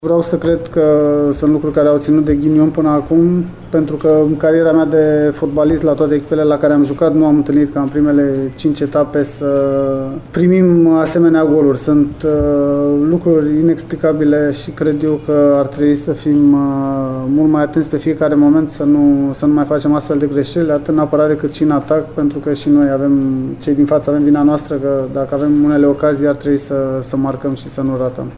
Ascultaţi declaraţiile în format audio ale lui Ianis Zicu.